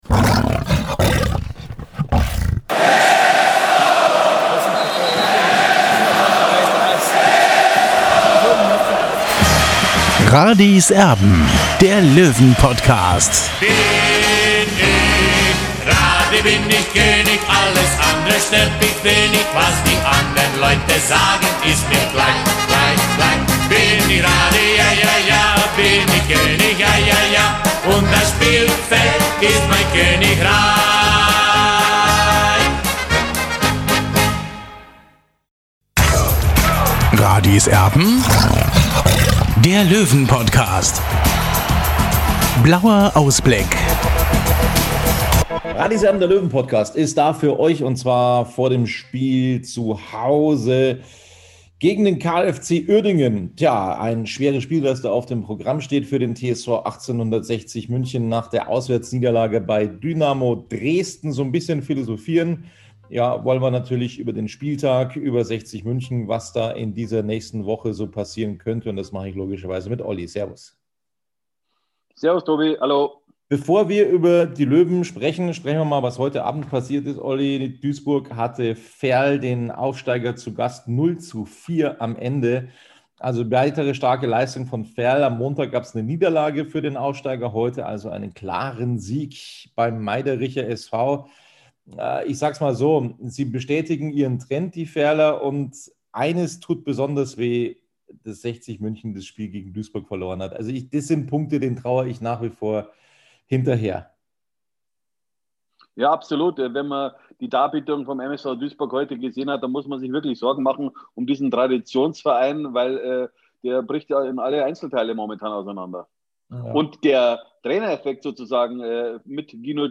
Als Bonus gibt's die wichtigsten Stimmen aus der Pressekonferenz